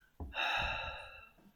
Le son que produit la respiration est a écouter attentivement.
Le souffle peut contenire une INTENTION de SOULAGEMENT et on entend discrètement "aaaaahhh".
souffle aaaaah.m4a